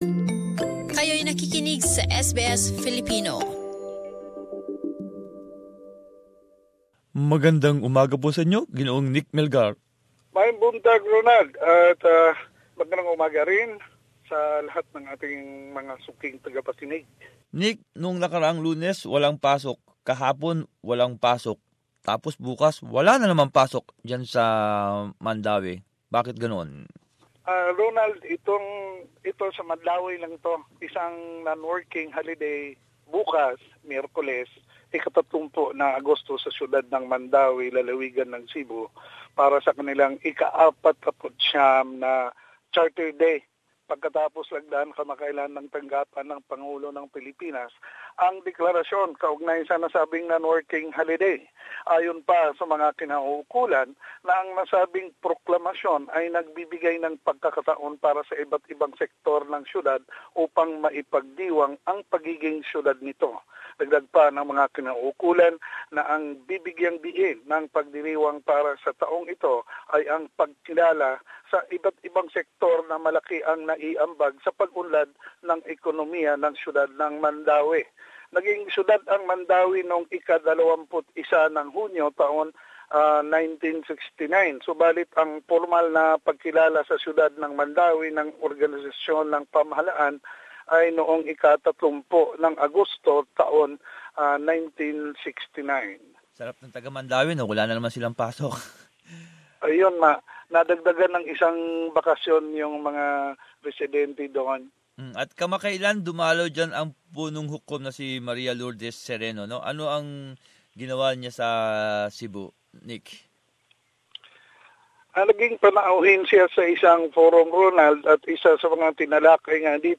Balitang Bisayas.